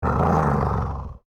Minecraft Version Minecraft Version latest Latest Release | Latest Snapshot latest / assets / minecraft / sounds / mob / wolf / angry / growl3.ogg Compare With Compare With Latest Release | Latest Snapshot
growl3.ogg